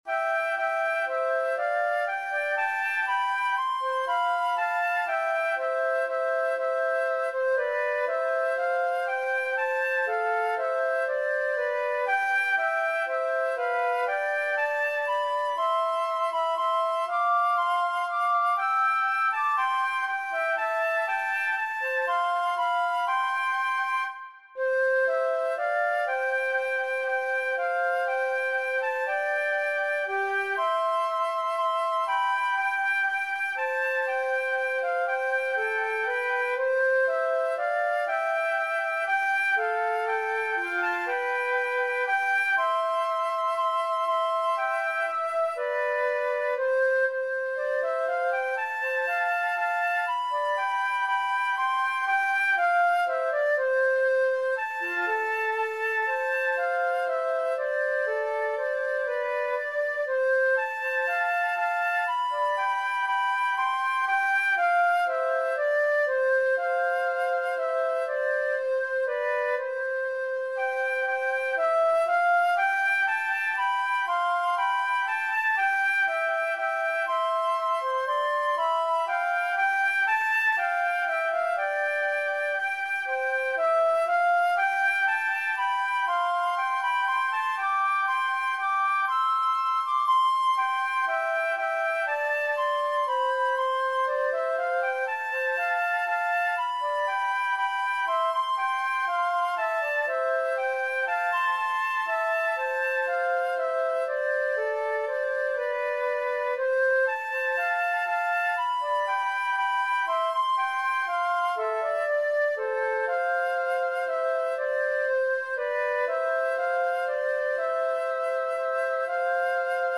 as a duet with two flutes
Voicing/Instrumentation: Flute Duet/Flute Ensemble Member(s)